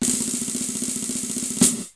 drum.wav